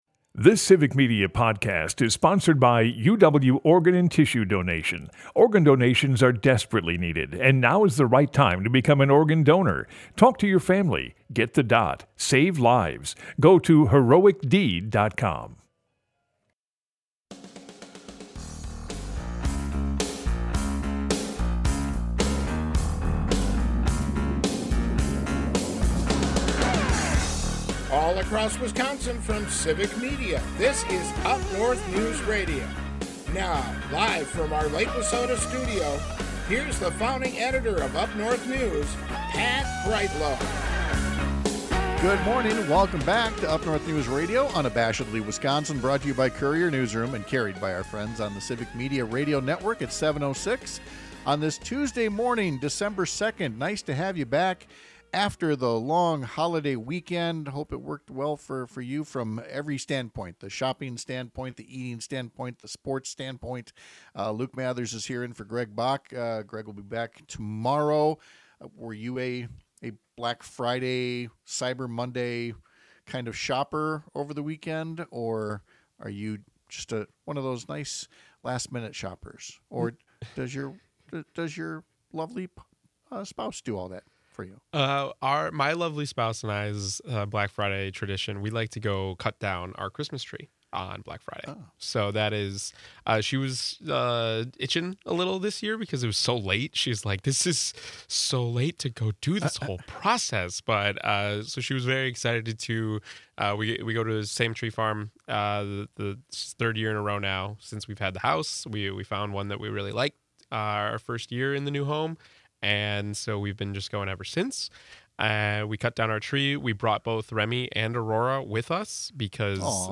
Broadcasts live 6 - 8 a.m. across the state!